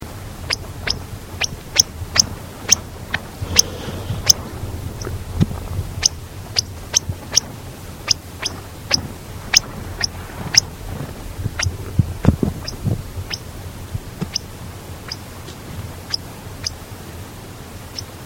Caprimulgus longirostris patagonicus - Dormilón patagónico
dormilonpatagonico.wav